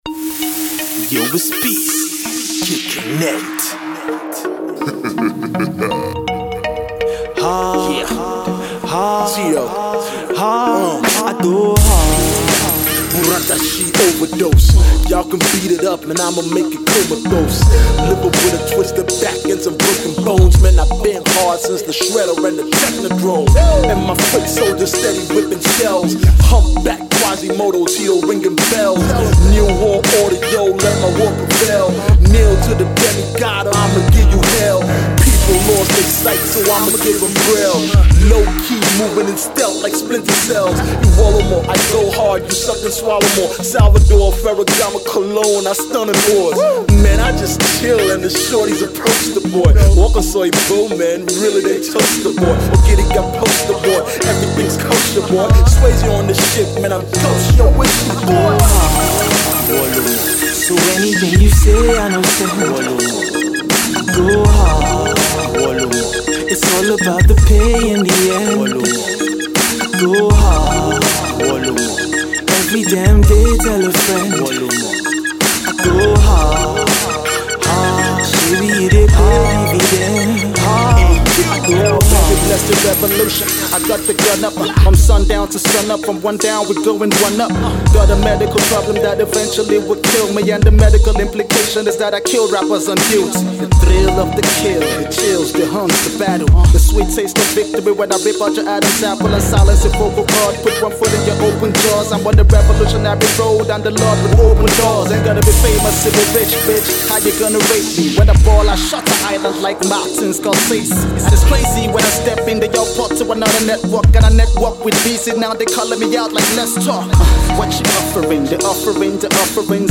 pure Hip-Hop song